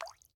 drip_water14.ogg